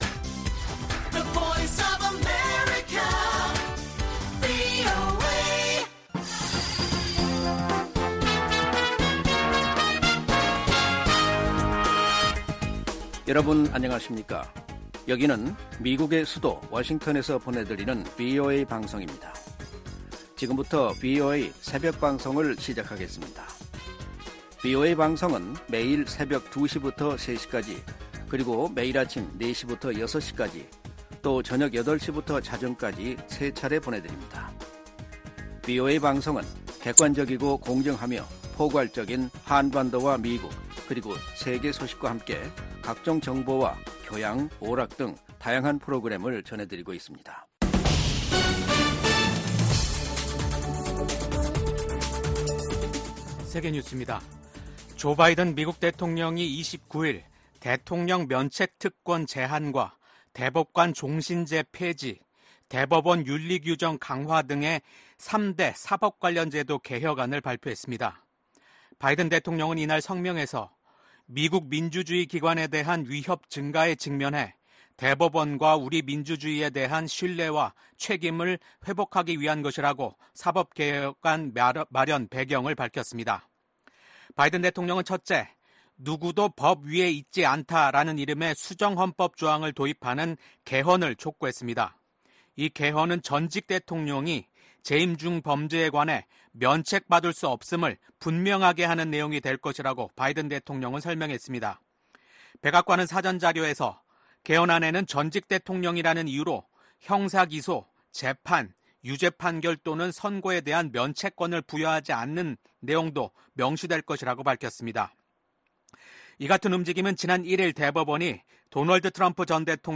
VOA 한국어 '출발 뉴스 쇼', 2024년 7월 31일 방송입니다. 미국이 핵확산금지조약(NPT) 평가 준비회의에서 북한의 완전한 비핵화가 목표라는 점을 재확인했습니다. 미국과 일본, 인도, 호주 4개국 안보협의체 쿼드(Quad) 외무장관들이 북한의 탄도미사일 발사와 핵개발을 규탄했습니다. 북한 주재 중국대사가 북한의 ‘전승절’ 기념 행사에 불참하면서, 북한과 중국 양국 관계에 이상 징후가 한층 뚜렷해지고 있습니다.